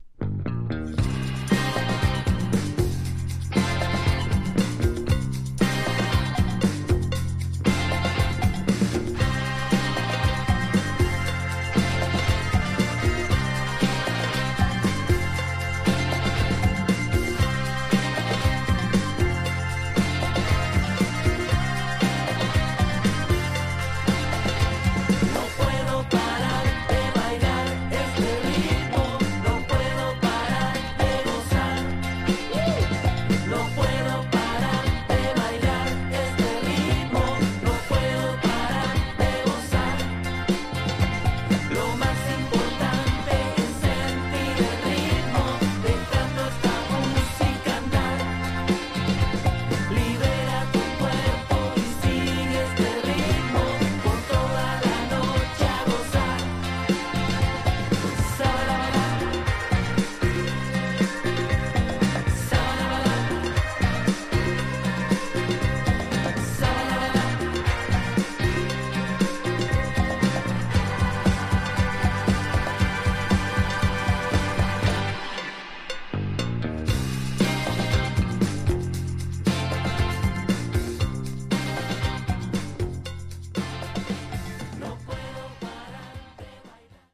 Tags: Psicodelico , Japan , Rock
Super psicodelic sounds
Pure latin psyche rock here from the Argentinian ensemble.